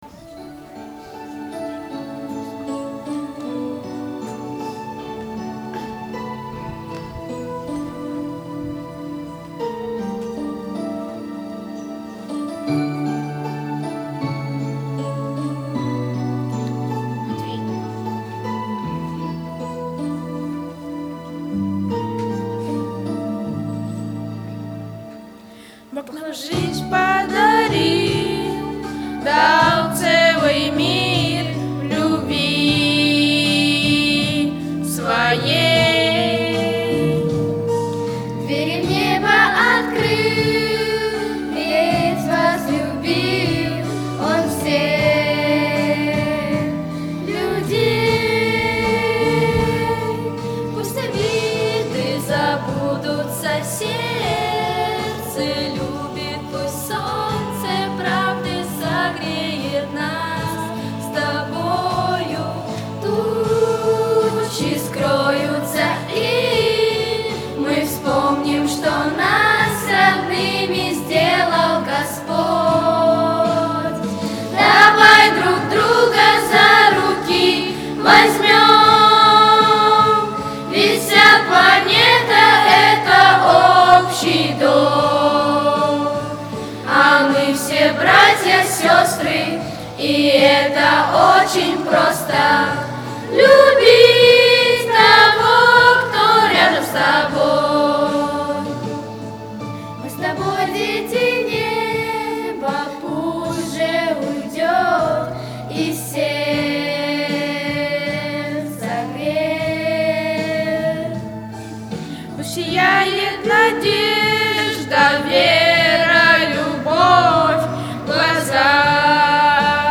on 2014-07-08 - Фестиваль христианской музыки и песни